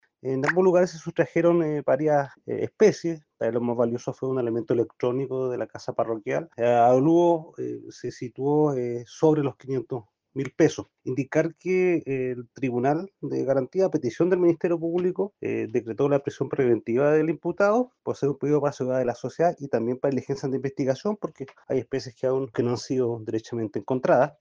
En tanto, el fiscal Javier Calisto señaló que el avalúo de las especies sustraídas, algunas de las cuales aún no han sido recuperadas, alcanza un monto cercano al medio millón de pesos.